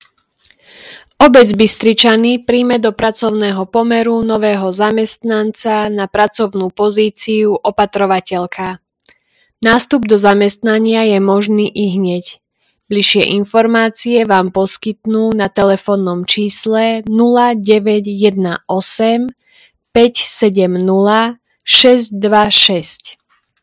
Hlásenie obecného rozhlasu – Ponuka práce – Opatrovateľka v Bystričanoch